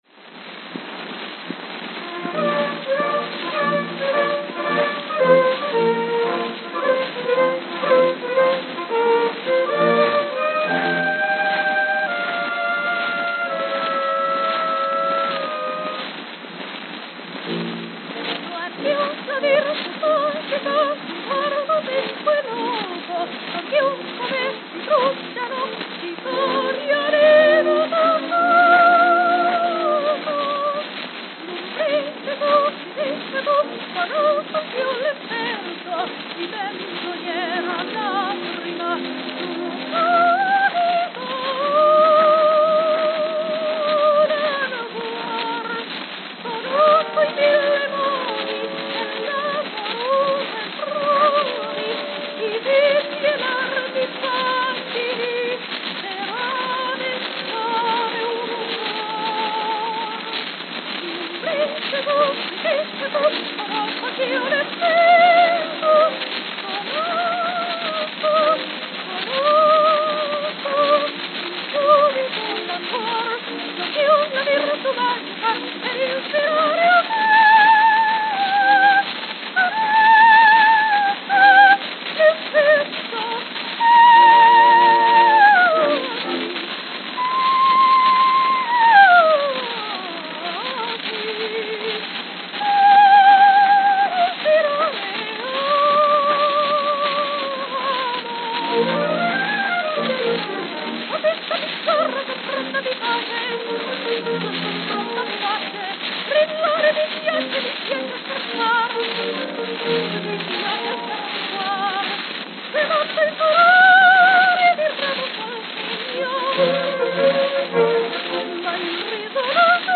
It was a prestigious tier featuring High Classical and Operatic selections with a base price of $2.00. It was analogous to Victor's Red Seal Label and Columbia's Symphony Series.
New York, New York New York, New York
Note: Very worn.